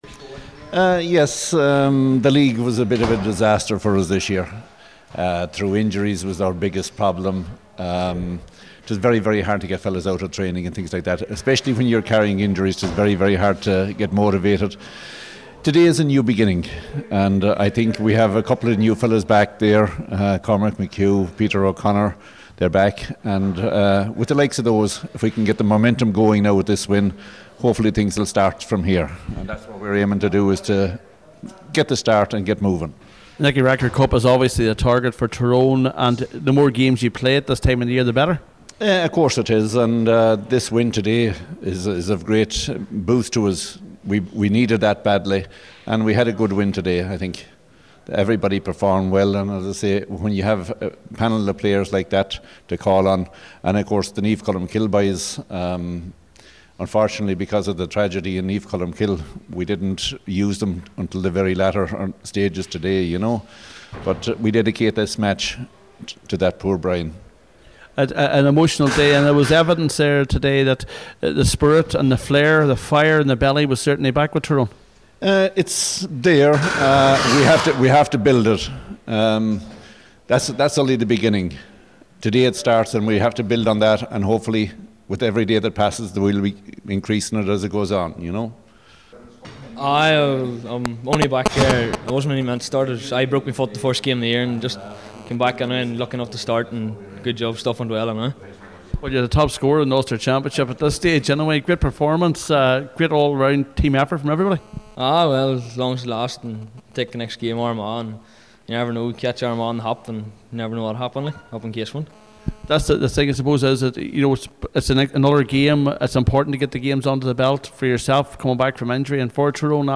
|titles=Reaction from Ulster Hurling Championship]